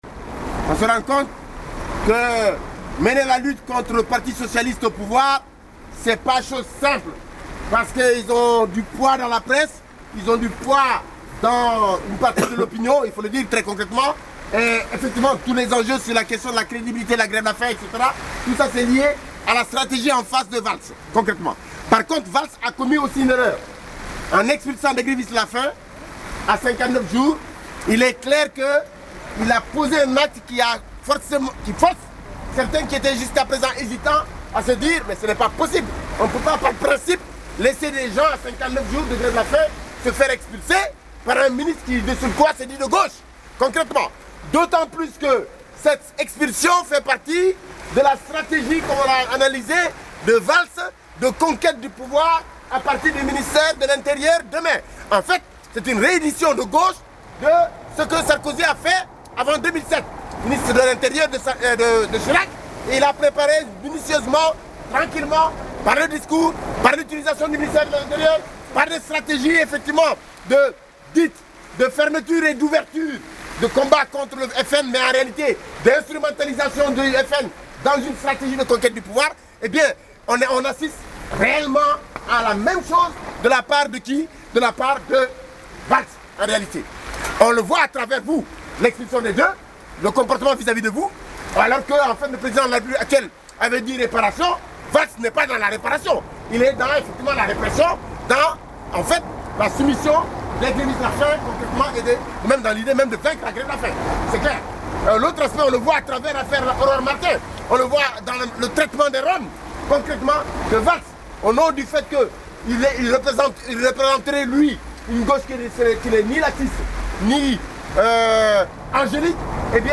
3 enregistrements audio effectués dans la tente des sans papiers du CSP59 en grève de la faim depuis 60 jours, ce Lundi 31 décembre 2012 vers 21h.
Le bruit de fond, c’est la pluie sur la toile de la tente.